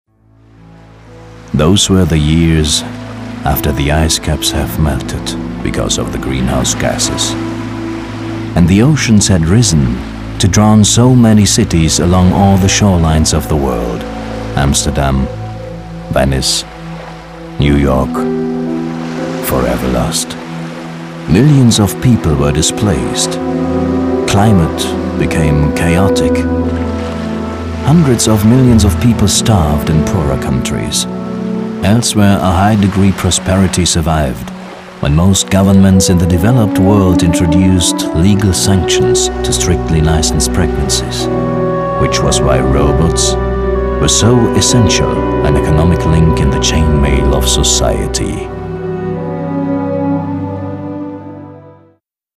deutscher Synchronsprecher, Off-Stimme, Moderator, Werbesprecher, Hörspiel, Trickstimme, Dialekte
Sprechprobe: Werbung (Muttersprache):
german voice over artist